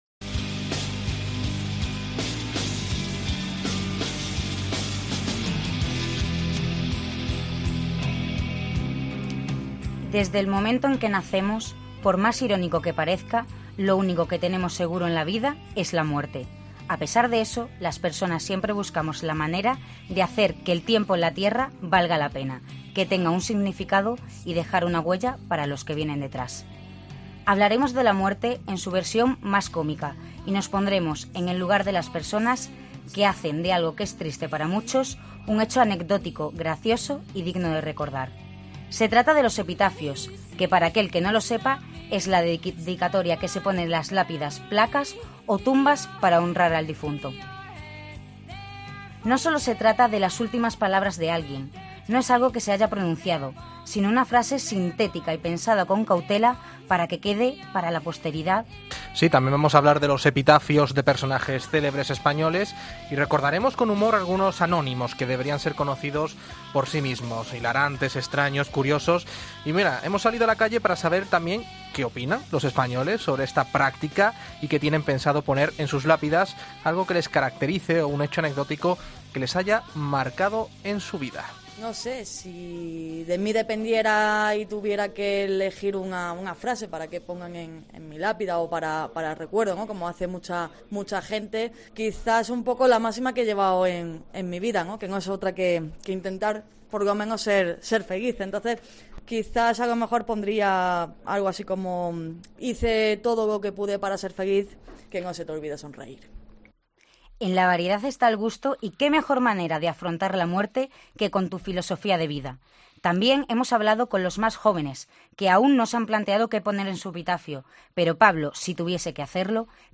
Hemos salido a la calle para saber qué opinan los españoles sobre esta práctica y qué tienen pensado poner en sus lápidas, algo que les caracterice o un hecho anecdótico que les haya marcado en su vida.